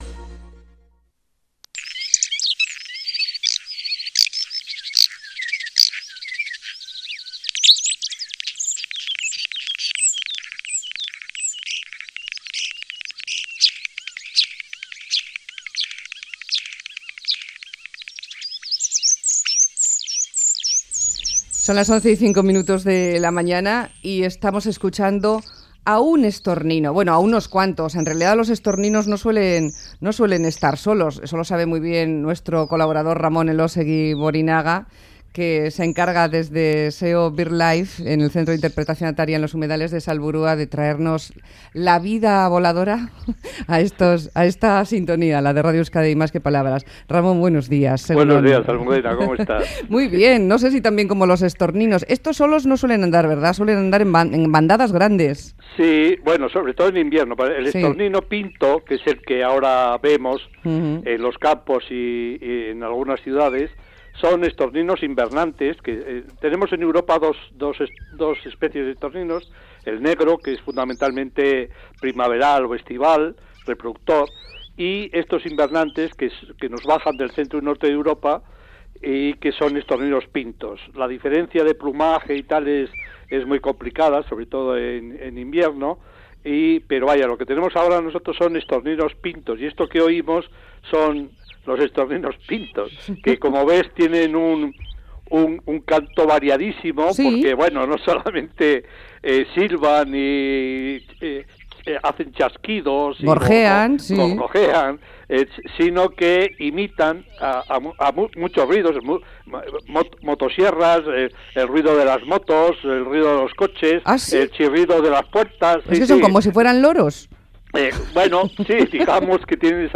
El estornino pinto, un pájaro plagiador